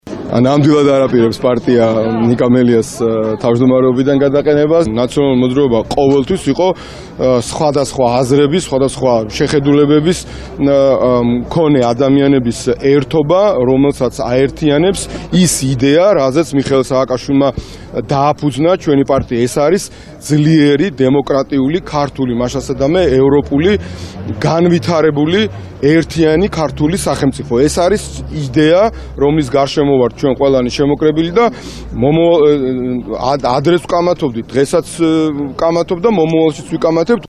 გიორგი ბარამიძის ხმა